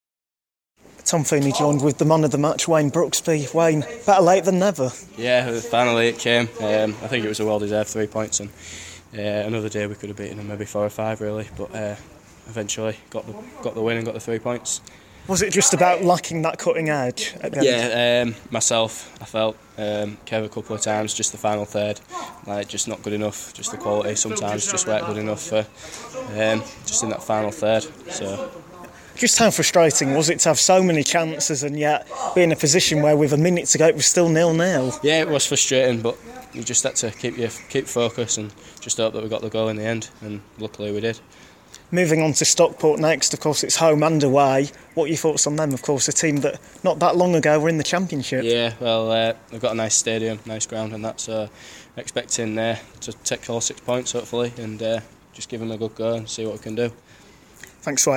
talks to the winger after the last minute win for Guiseley.